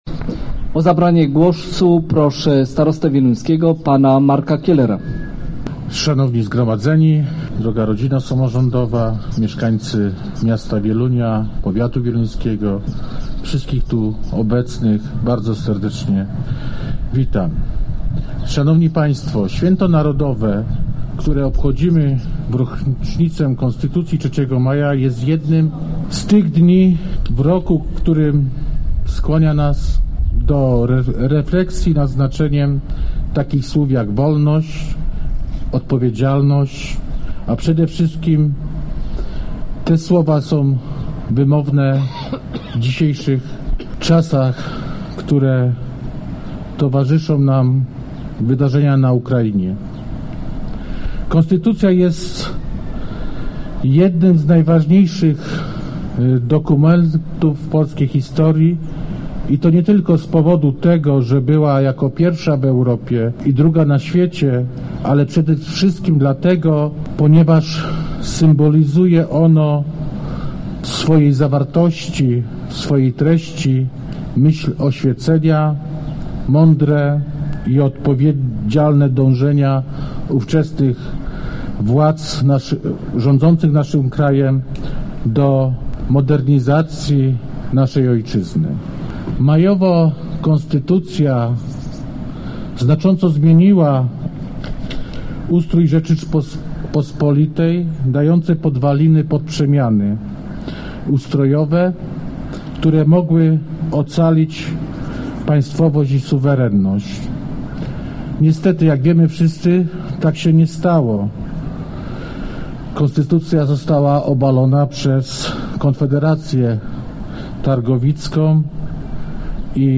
W Wieluniu uczczono 231. rocznicę uchwalenia Konstytucji 3 Maja. Obchody rozpoczęły się od mszy świętej w wieluńskiej kolegiacie, po czym uczestnicy przeszli pod pomnik papieża Jana Pawła II, gdzie miały miejsca okolicznościowe przemówienia.